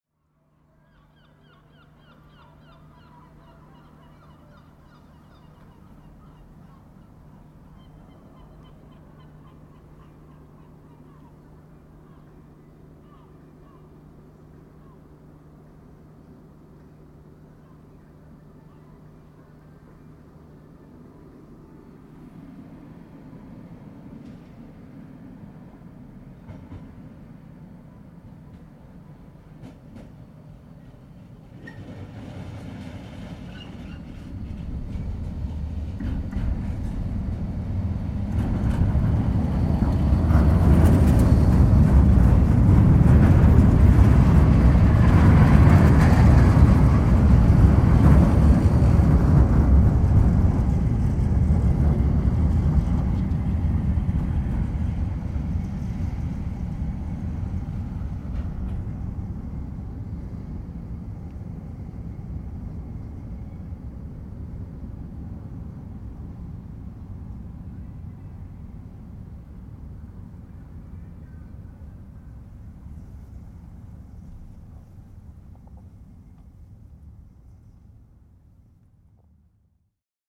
Spanning the Douro River, the Dom Luís I Bridge in Porto provides a unique moment of calm in the early hours of the day. The recording captures the peaceful atmosphere, where the distant calls of seagulls and the quiet hum of the city fill the air.
In this tranquil setting, the Metro train makes its dramatic entrance, its sound gradually building as it approaches, becoming louder until it passes by, leaving only the faint echoes of its journey. The bridge remains still, with the occasional shift of air and the gentle sounds of the river below, all while the city begins to stir.